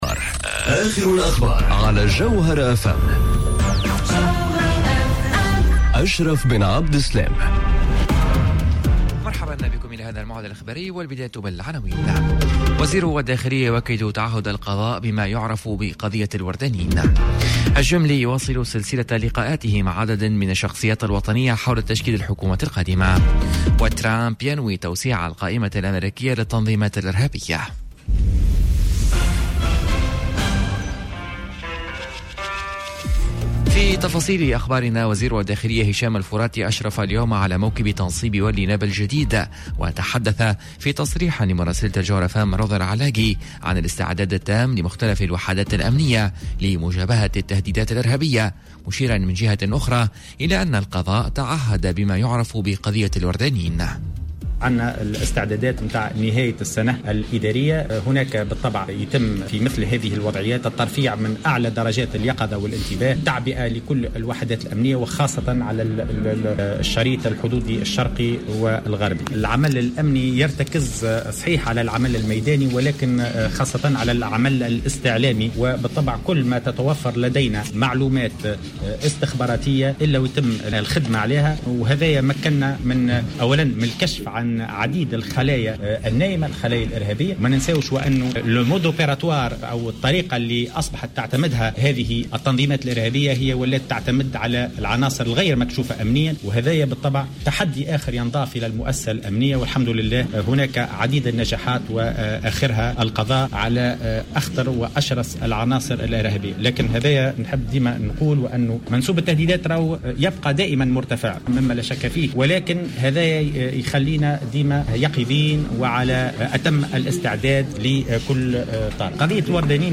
Journal info 12h00 du mercredi 27 novembre 2019